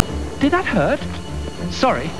Spot Sound Bytes!
From Spider-Man: the Animated Series.